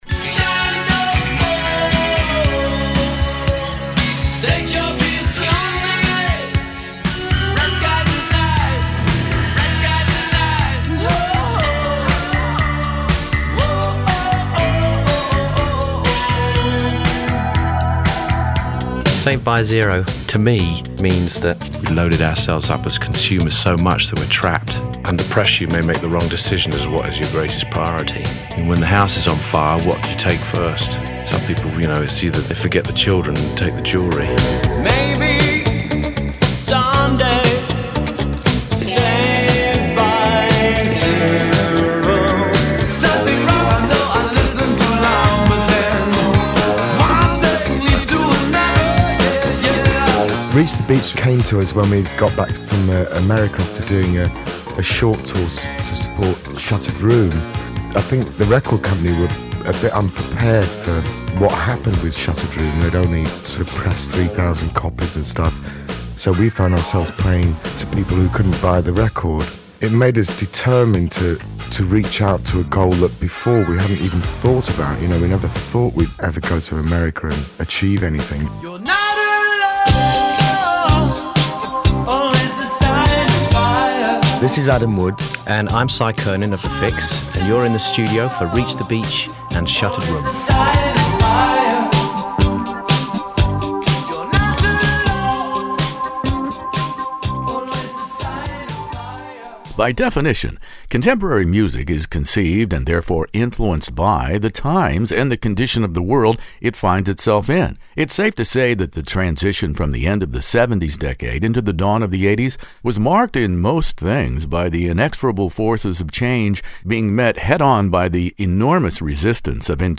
The Fixx "Reach the Beach" interview with Cy Curnin, Adam Woods In the Studio